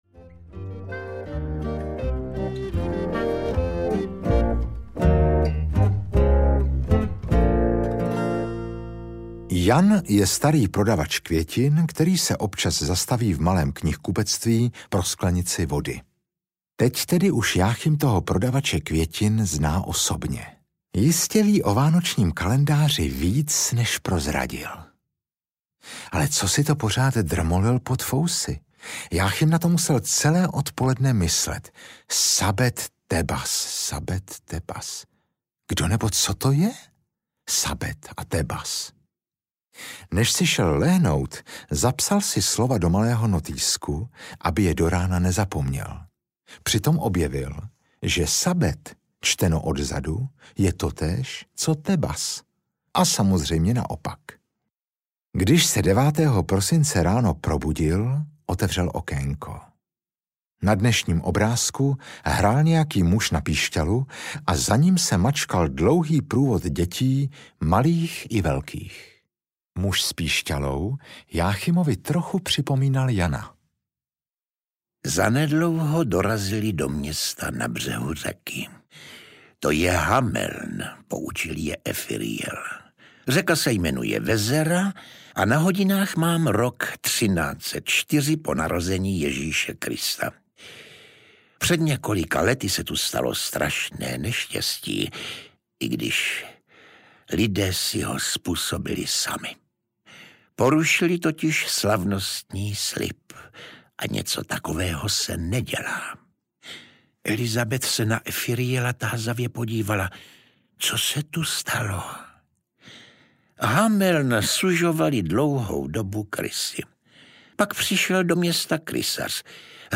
Kouzelný kalendář audiokniha
Ukázka z knihy